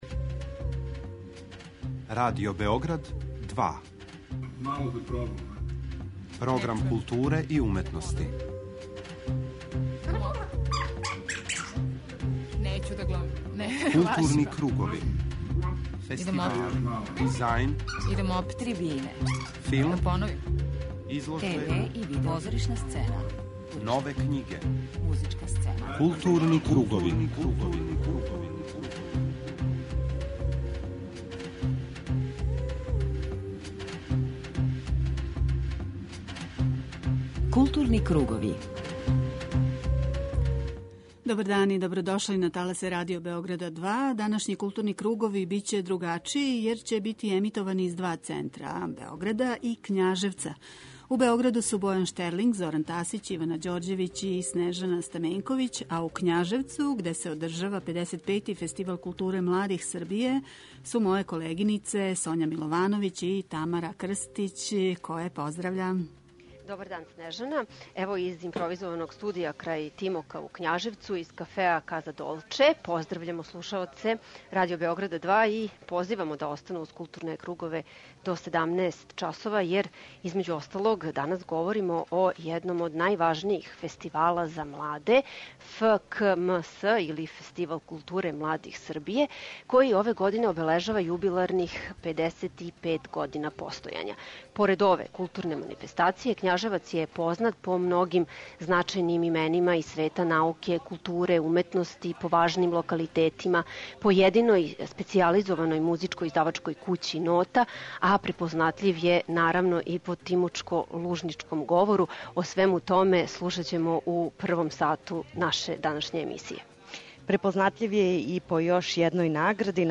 У директном радио-преносу из Књажевца, у првом делу 'Културних кругова' говоримо о програму јубиларног 55. Фестивала културе младих Србије, једној од најважнијих манифестација посвећених младим ствараоцима.